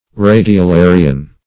(r[=a]`d[i^]*[-o]*l[=a]"r[i^]*an)